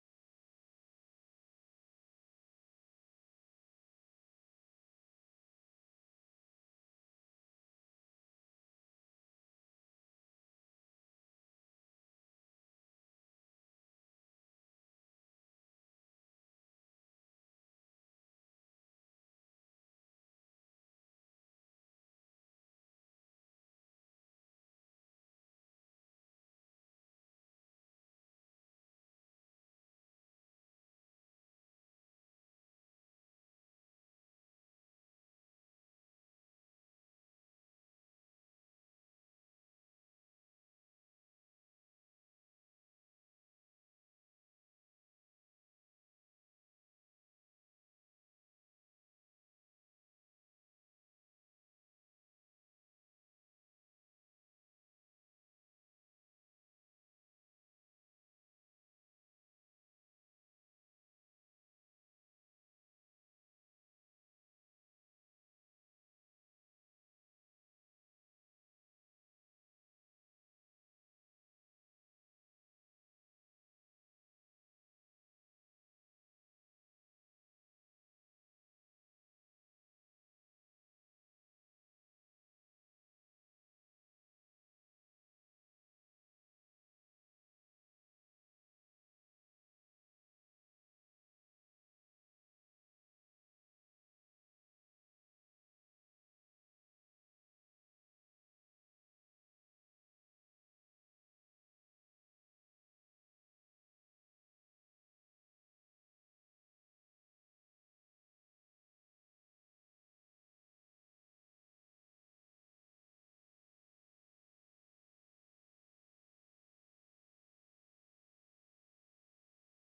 output_silence.mp3